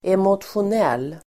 Ladda ner uttalet
emotionell adjektiv, emotional Uttal: [emotsjon'el:]